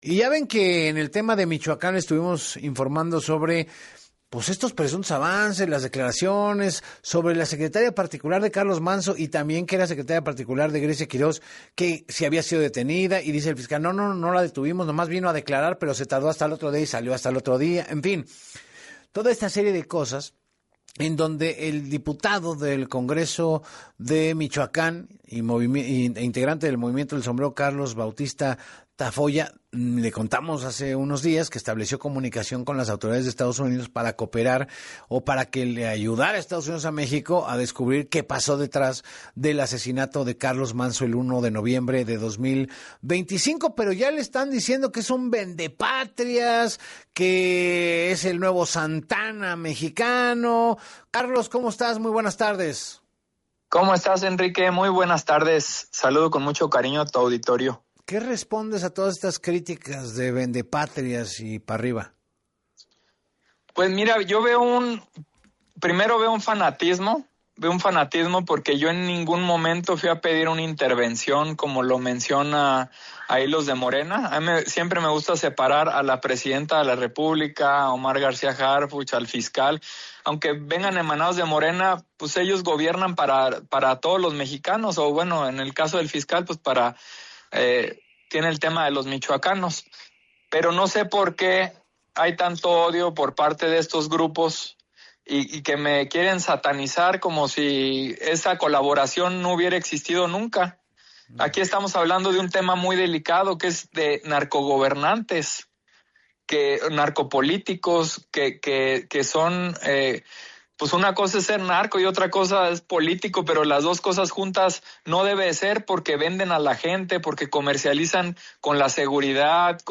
En entrevista para “Así Las Cosas con Enrique Hernández Alcázar”, Bautista Tafolla explicó que la identificación de este centenar de sospechosos fue posible gracias a la cooperación técnica con la Oficina de Alcohol, Tabaco, Armas de Fuego y Explosivos (ATF) de Estados Unidos.